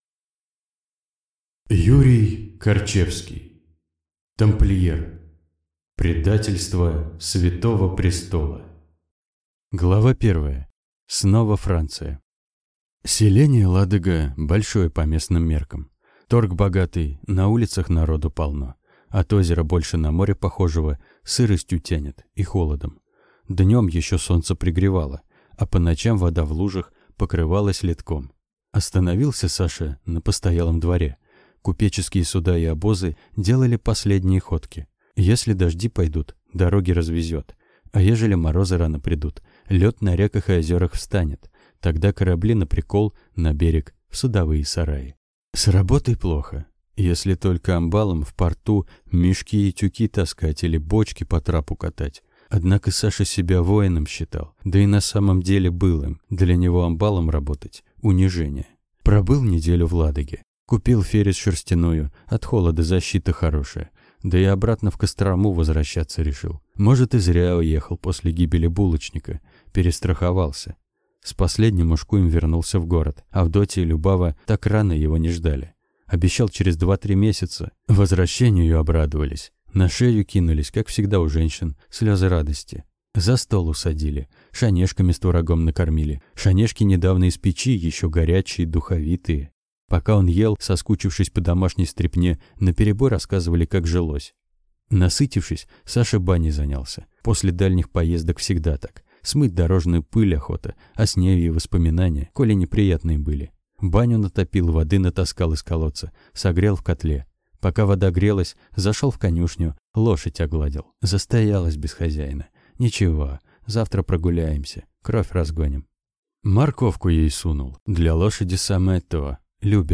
Аудиокнига Тамплиер. Предательство Святого престола | Библиотека аудиокниг